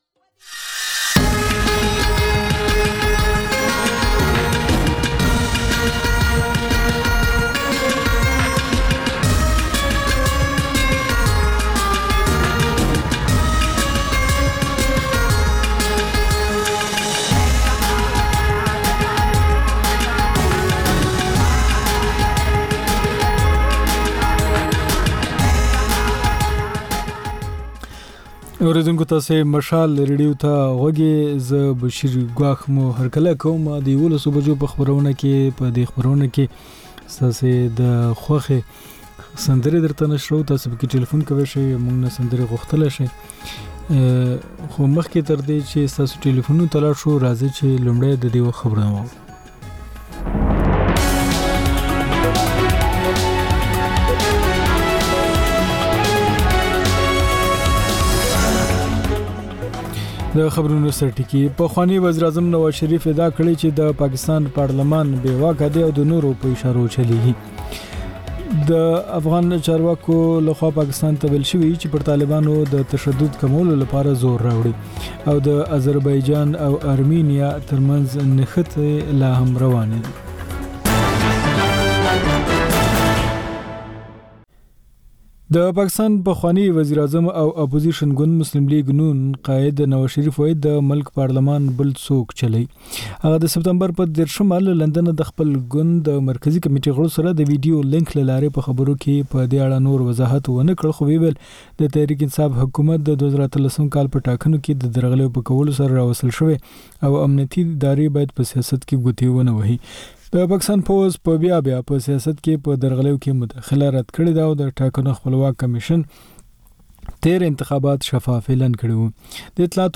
په دې خپرونه کې تر خبرونو وروسته له اورېدونکیو سره په ژوندۍ بڼه خبرې کېږي، د هغوی پیغامونه خپرېږي او د هغوی د سندرو فرمایشونه پوره کول کېږي.